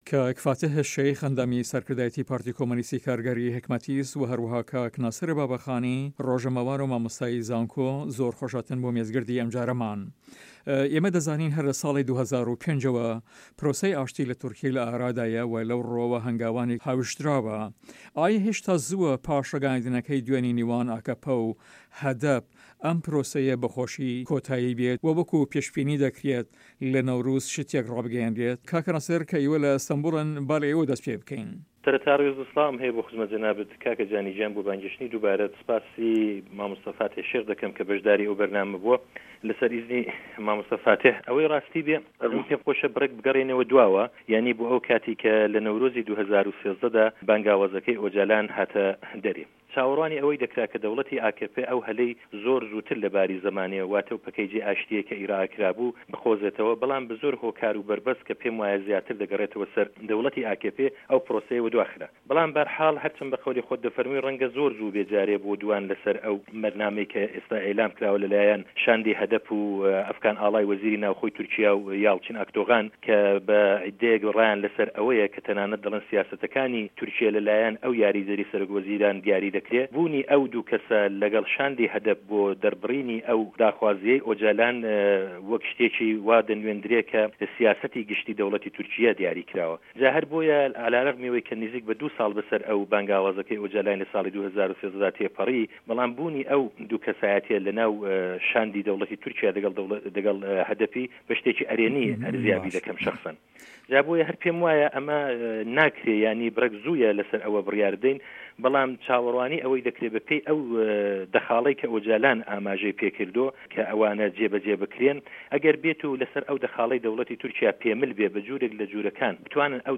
مێز گرد:ئاشتی، چه‌ک دانان یان ئاگر به‌س. PKK.HDP.AKP قه‌ندیل.